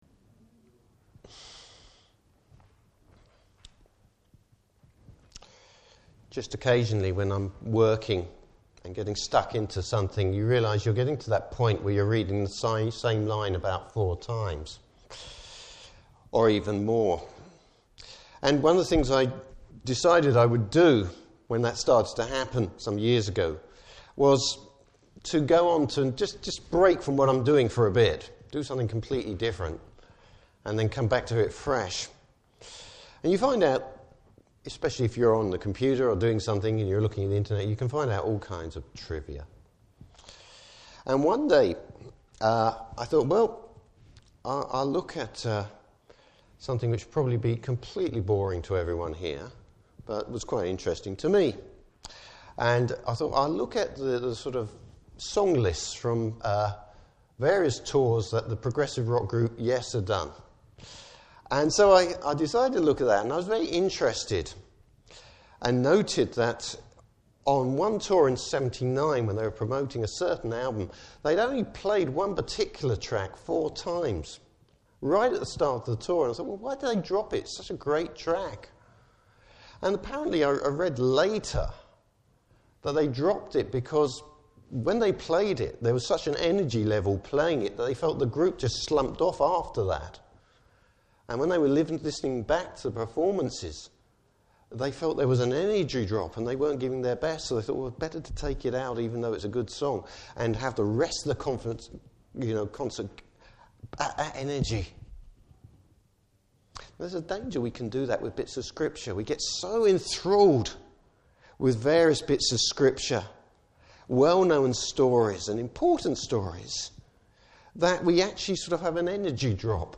Service Type: Morning Service Bible Text: Matthew 4:12-25.